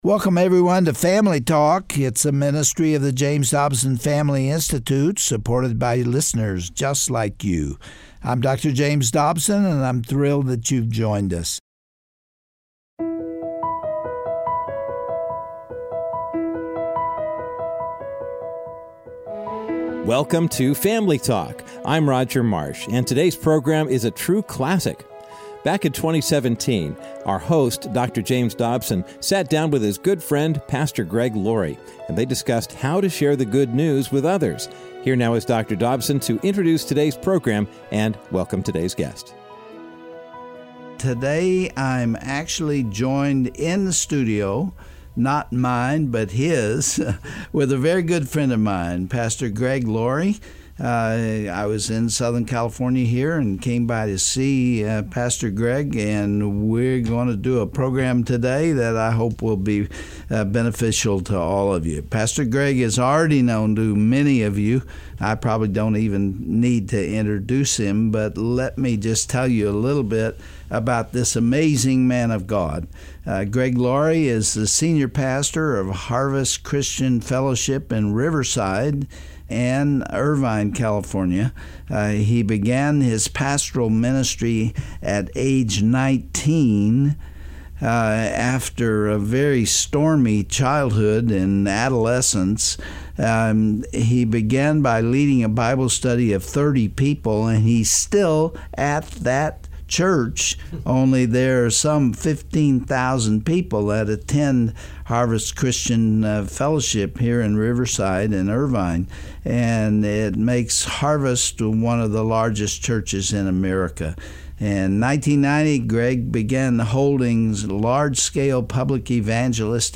On today’s edition of Family Talk, Dr. James Dobson and Pastor Greg Laurie discuss Greg’s personal testimony and his 2016 book, Tell Someone: You Can Share the Good News. Laurie also emphasizes the importance of imparting the gospel to those that God places in our lives, and encourages all believers, even those without the gift of evangelism, to tell someone about Jesus Christ.